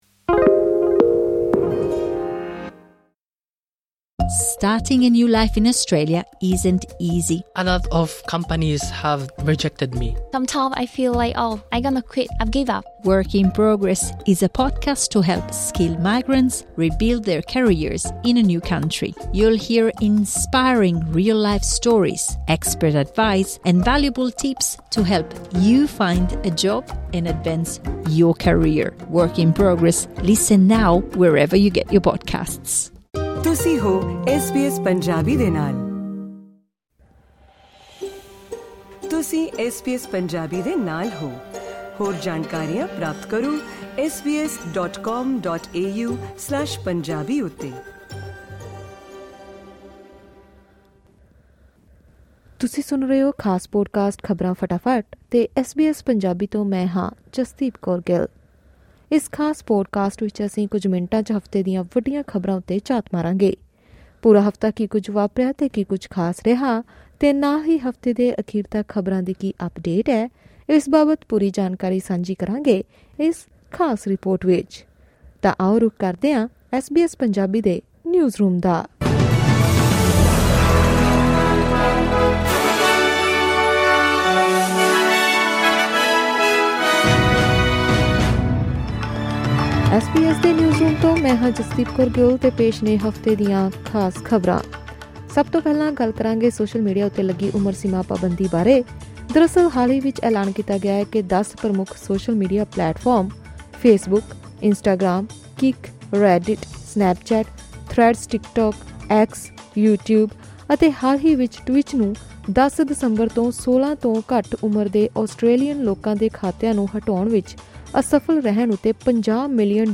ਖ਼ਬਰਾਂ ਫਟਾਫੱਟ: ਸੋਸ਼ਲ ਮੀਡੀਆ ਬੈਨ ਜੁਰਮਾਨੇ, ਏਸ਼ੀਆ ਵਿੱਚ ਆਏ ਹੜ੍ਹਾਂ ਅਤੇ ਧਰਮਿੰਦਰ ਦੀਆਂ ਅਸਥੀਆਂ ਦੇ ਵਿਸਰਜਨ ਸਮੇਤ ਇਸ ਹਫ਼ਤੇ ਦੀਆਂ ਹੋਰ ਪ੍ਰਮੁੱਖ ਖ਼ਬਰਾਂ 05:20 Weekly News Wrap.